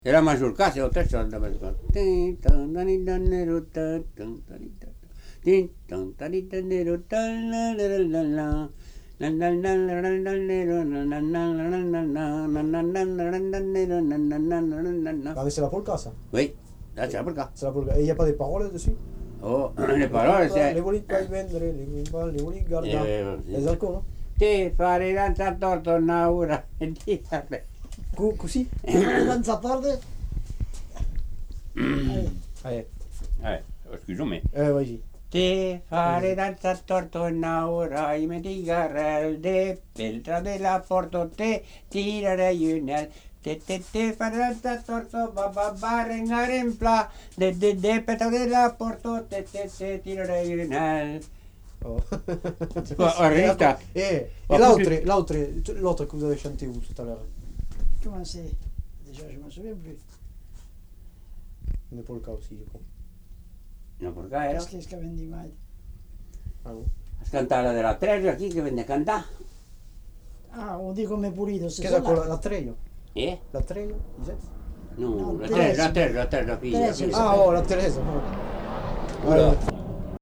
Aire culturelle : Lauragais
Lieu : Villaudric
Genre : chant
Effectif : 1
Type de voix : voix d'homme
Production du son : chanté